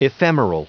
Prononciation du mot ephemeral en anglais (fichier audio)
Prononciation du mot : ephemeral